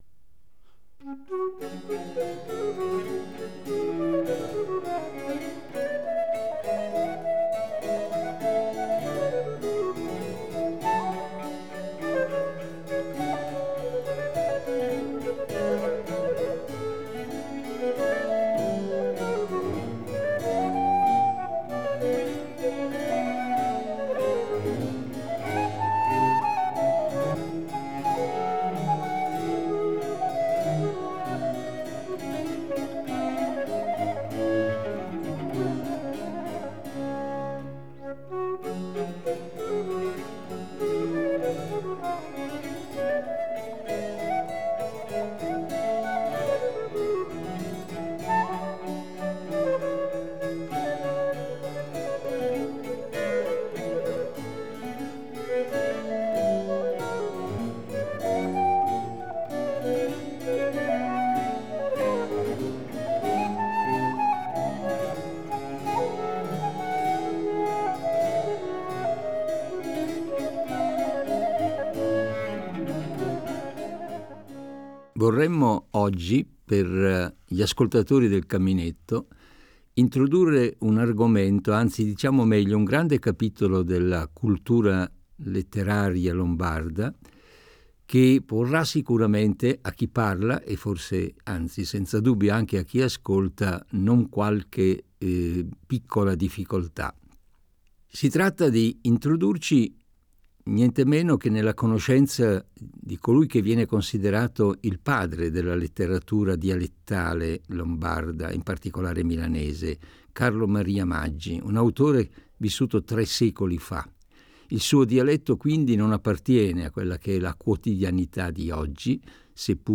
"Lettura al caminetto", 4 aprile 1999